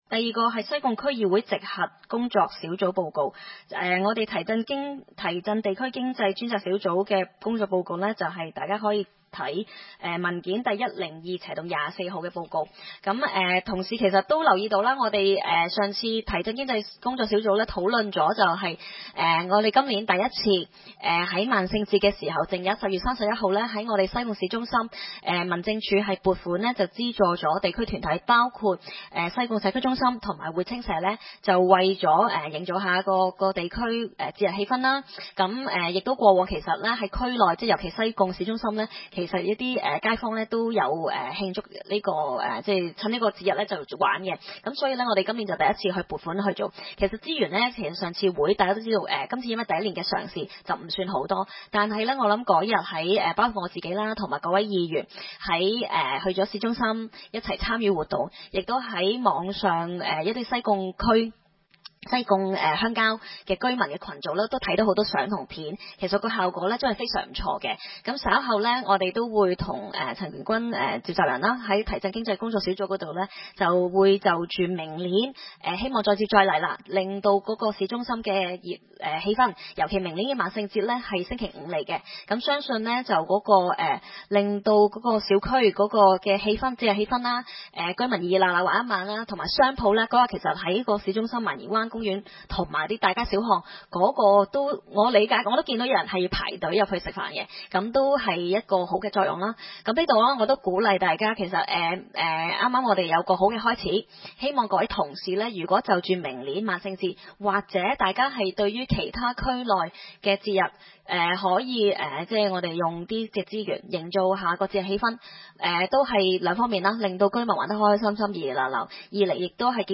區議會大會的錄音記錄
西貢將軍澳政府綜合大樓三樓